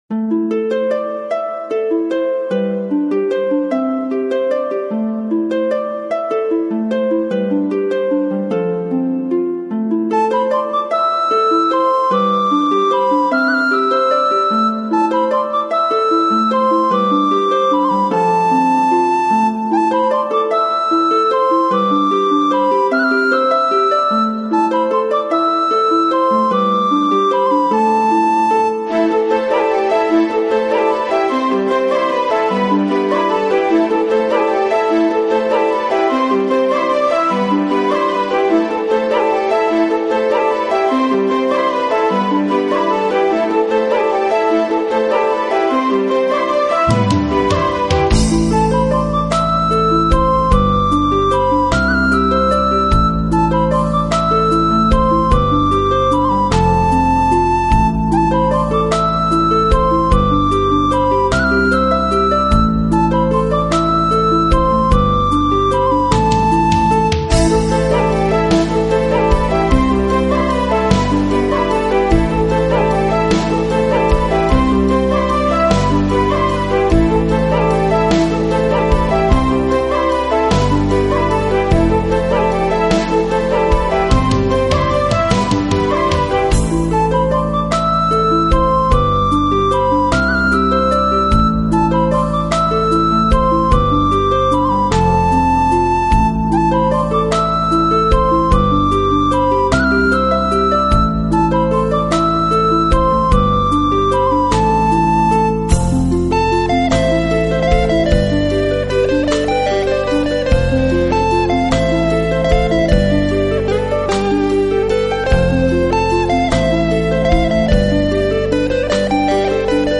【新世纪音乐】